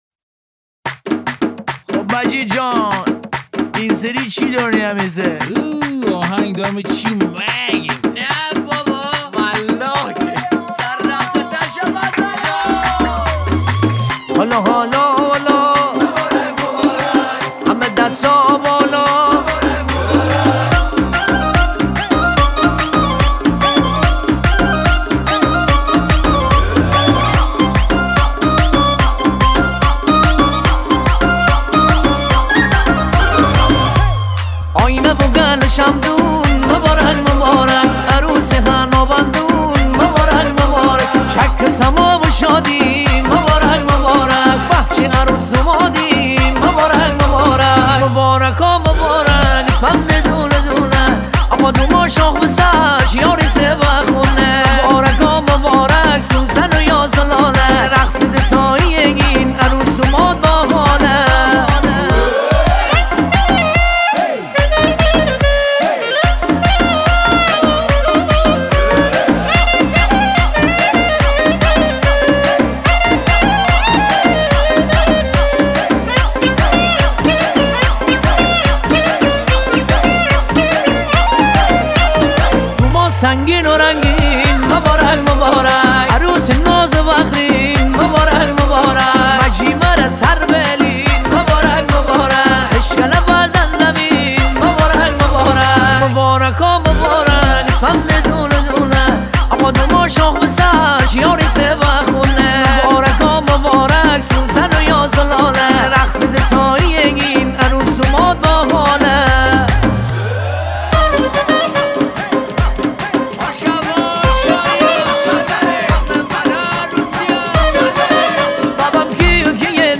شاد
آهنگی در سبک آهنگ های شاد مازندرانی